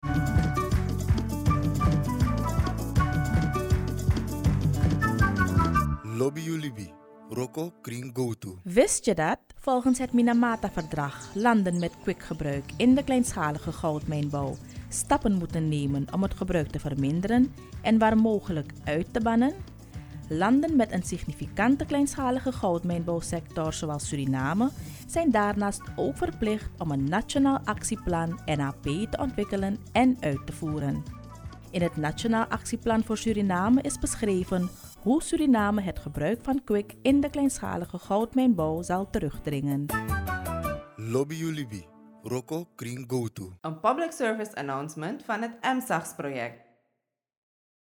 EMSAGS NL Radiospot 4